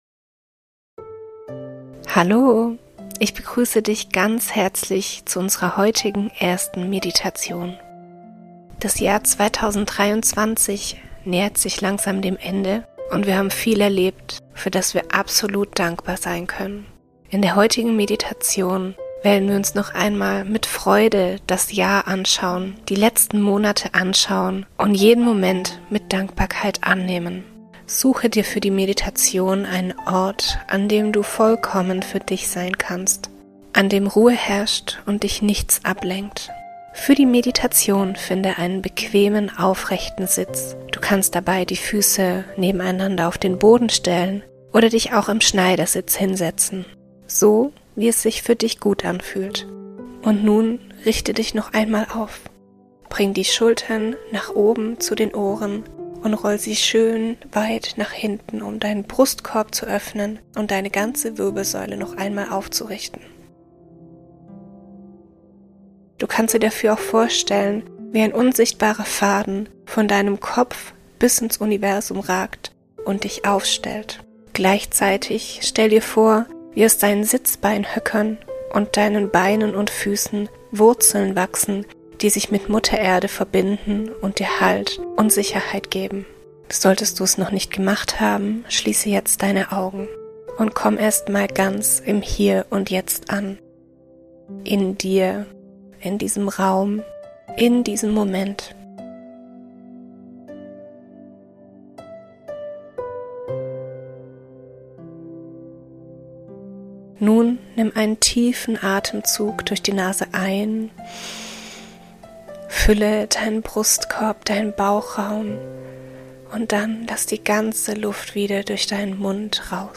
Dankbarkeitsmeditation für 2023 ~ Vergissdeinnicht-Podcast Podcast